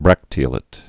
(brăktē-ə-lĭt, -lāt)